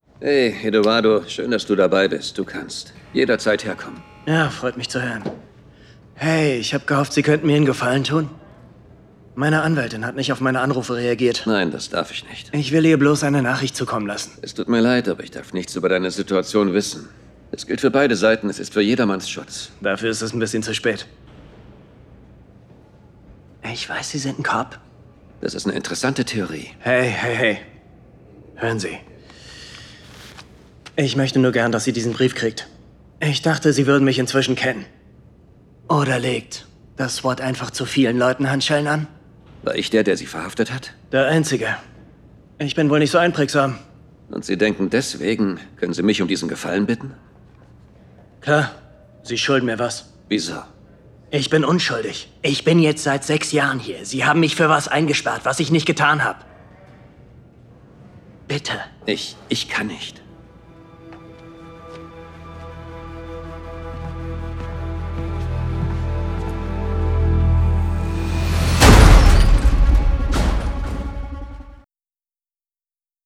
★ Rolle: Eduardo